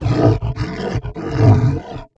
MONSTER_Exhausted_04_mono.wav